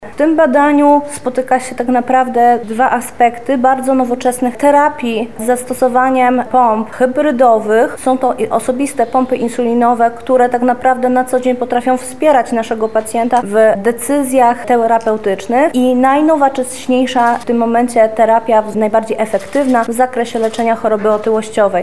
Dziś (22.01) odbyła się konferencja prasowa poświęcona nowoczesnej farmakoterapii z zaawansowaną technologią diabetologiczną.
konferencja-medyczny2.mp3